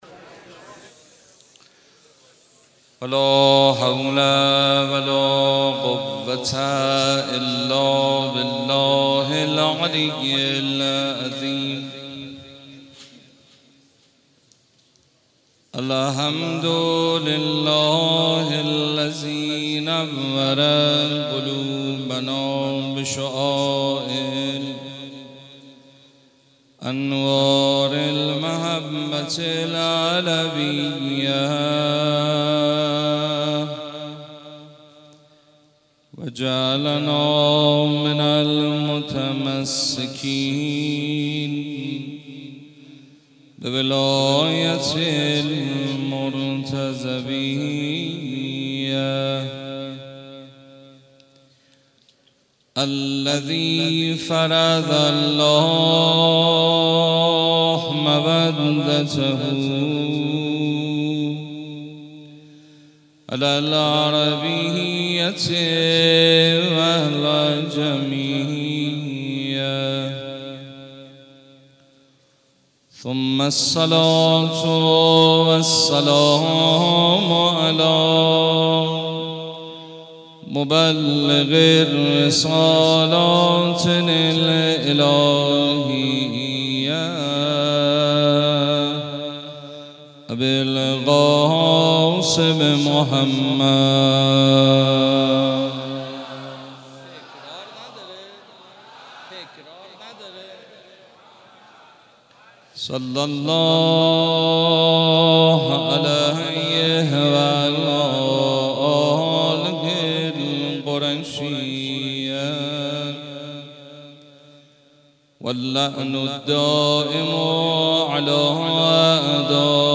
Layer ۱ ریشه > ماه رمضان > مناجات > 1393 > شب ضربت خوردن حضرت امیرالمومنین علی B 19 رمضان 1435 > سخنرانی > 1- ÈÎÔ Çæá